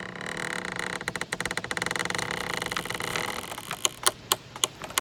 coffin.ogg